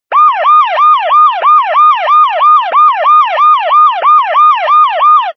Categories: Sound Effect